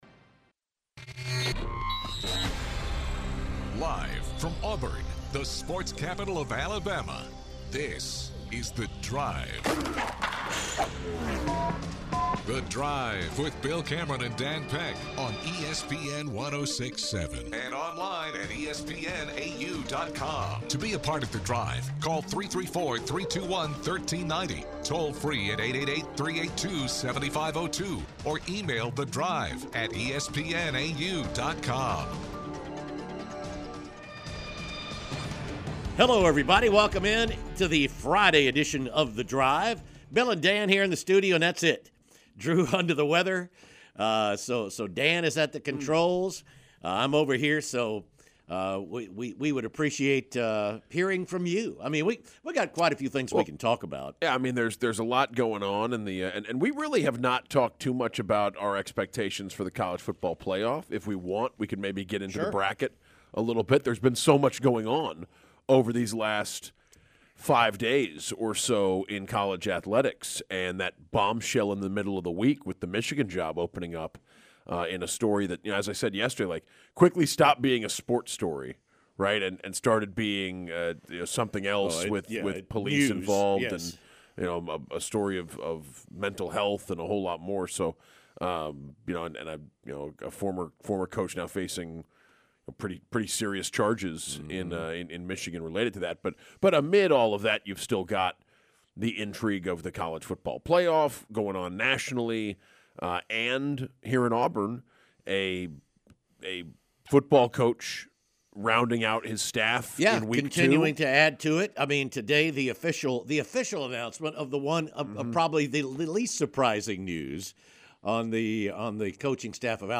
Callers ask about deciding between one of Auburn's current quarterbacks or hitting the portal for a solution from outside.